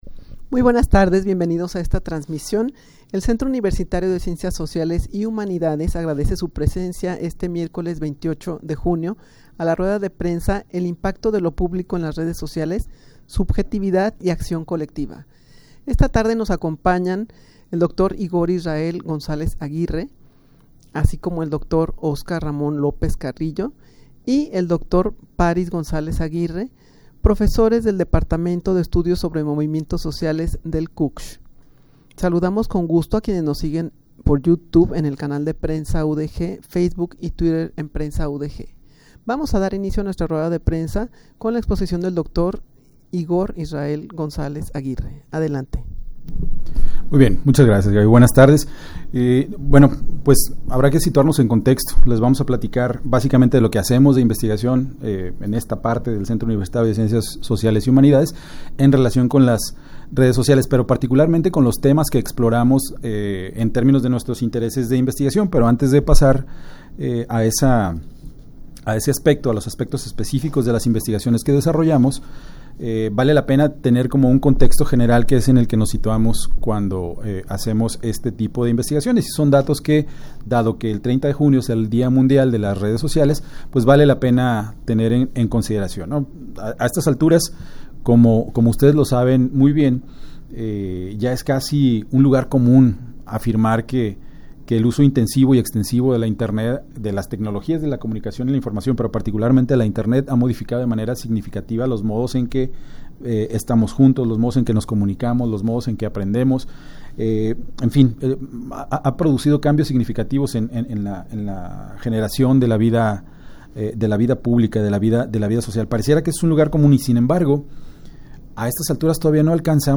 Audio de la Rueda de Prensa
rueda-de-prensa-el-impacto-de-lo-publico-en-las-redes-sociales-subjetividad-y-accion-colectiva.mp3